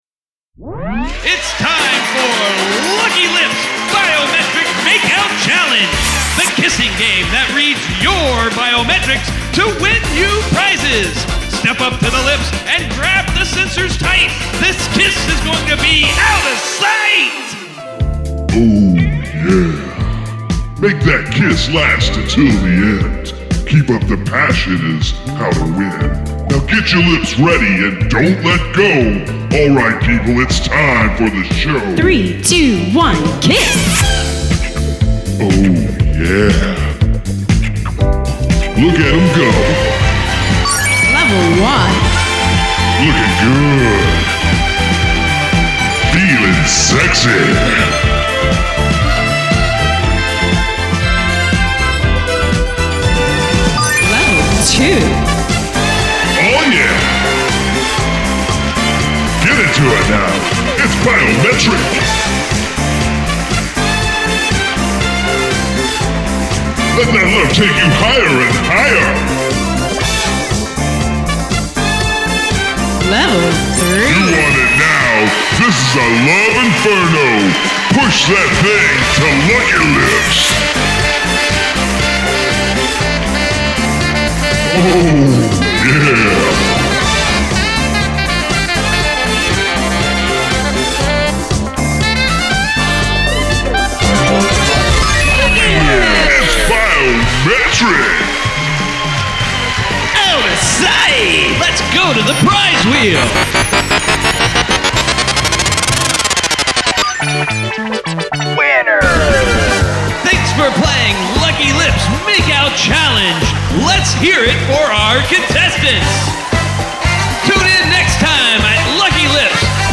Score and Voice Over